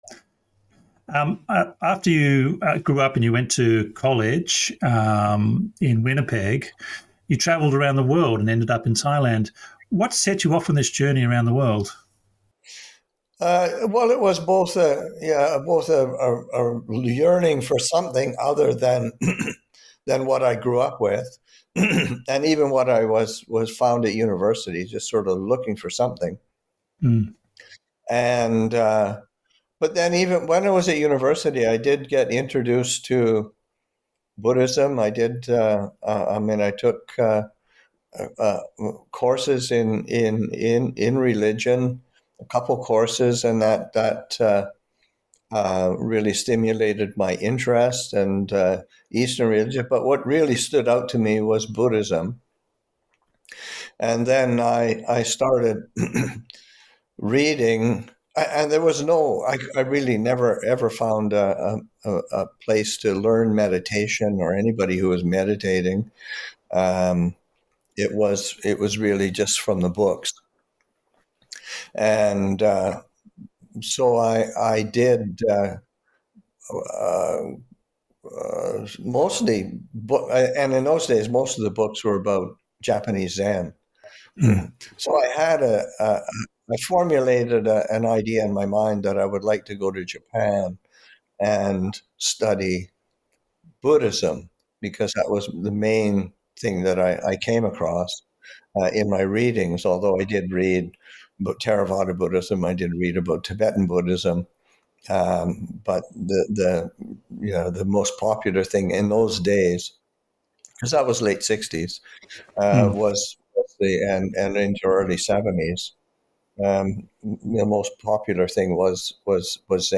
Online interview